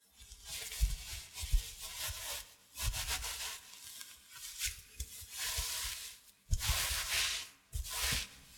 household
Cloth Sweater Scrub